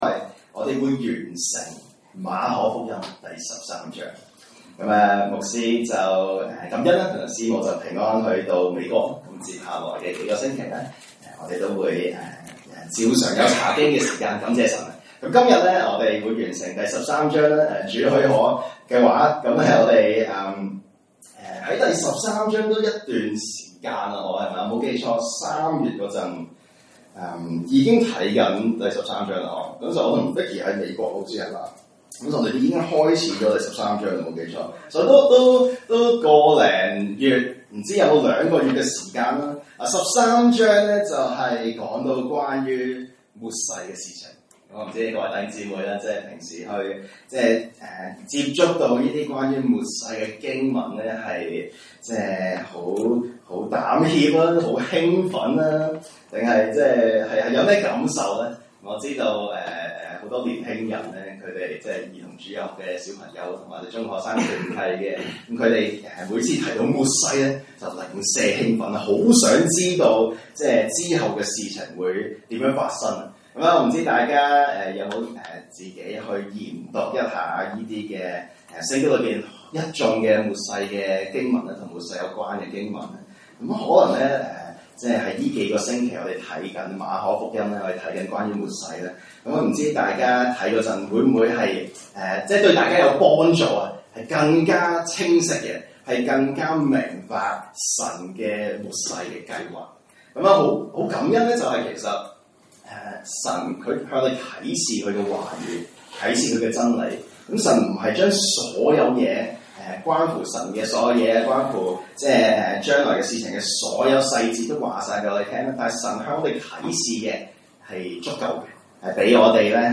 來自講道系列 "查經班：馬可福音"